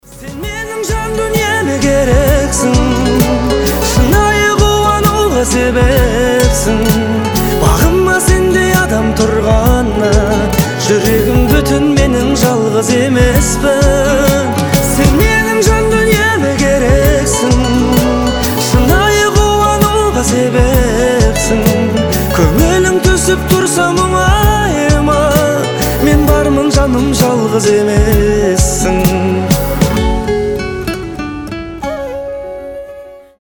• Качество: 320, Stereo
казахские